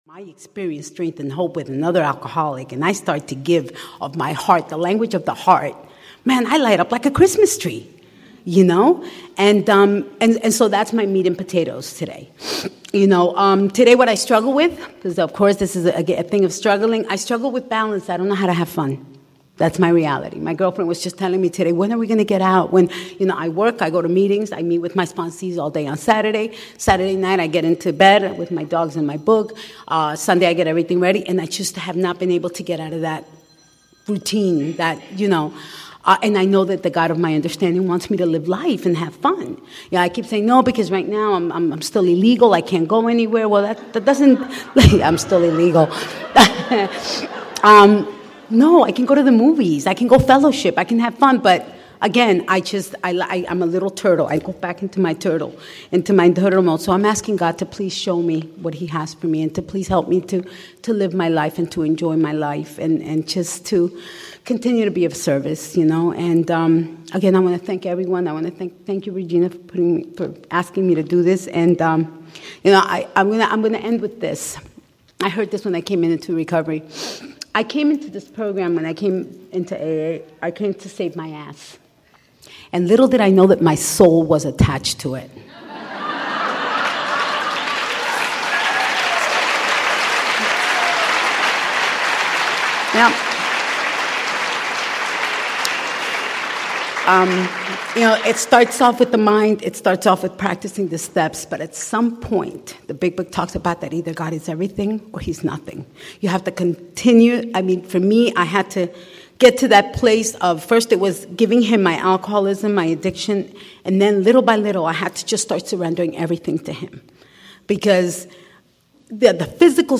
speaking at Al-Anon Family Groups Florida South Area 10 Convention, August 2017, Miami, FL